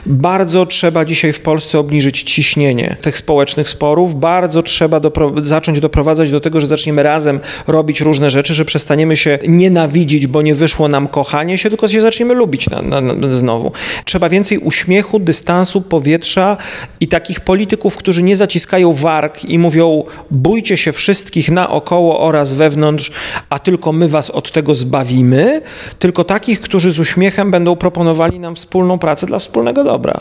– Budynek sejmu nie powinien kojarzyć z cyrkiem, a miejsce w którym ludzie ze sobą rozmawiają – tak Szymon Hołownia niedawno mówił na antenie Twojego Radia.